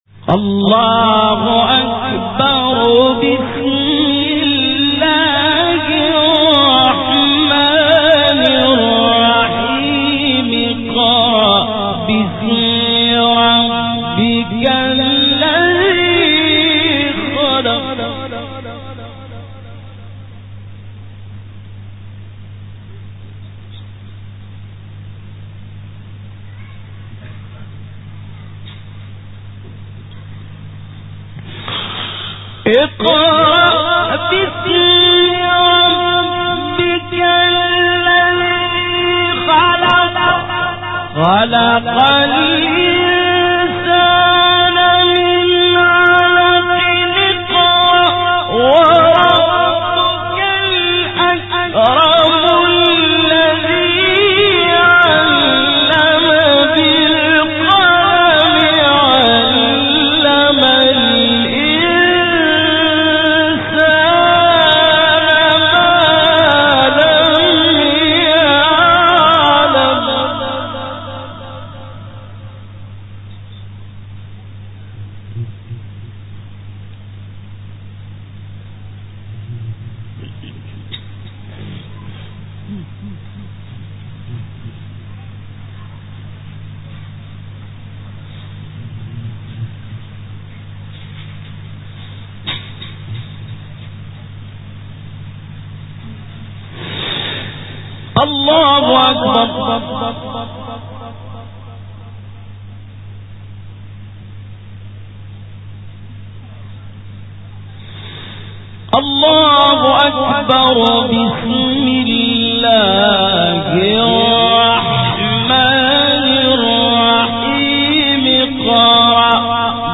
تلاوت سوره علق استاد محمد لیثی | نغمات قرآن | دانلود تلاوت قرآن